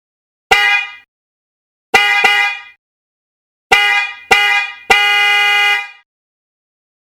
※音は試聴用に録音したもので実際の吹鳴音と異なる場合があります。
伝統のヨーロピアンサウンドホーン
伝統の原点となる周波数480Hz／400Hzを用いたヨーロピアンホーンです。渦巻ホーンの特長である柔らかなサウンドを奏でます。
余裕の大音量 113dB／2m
• 中音域タイプ
Hi：480Hz／Lo：400Hz